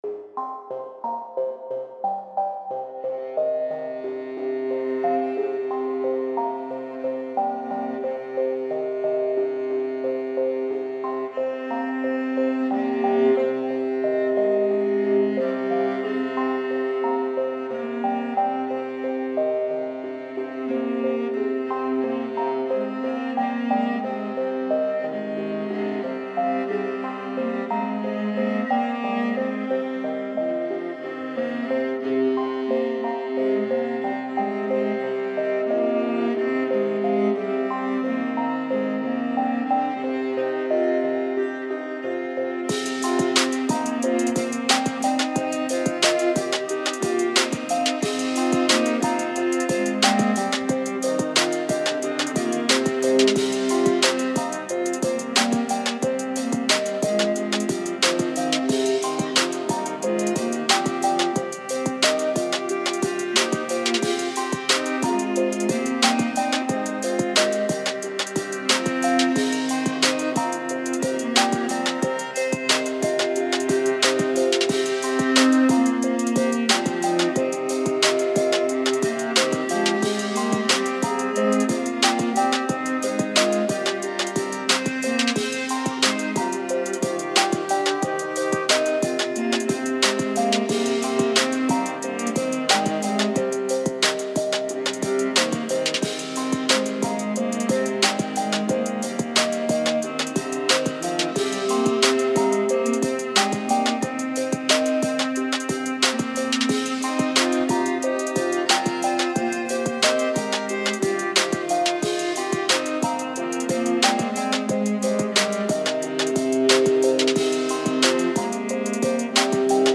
Here is a little draft with beats, viola and violin this morning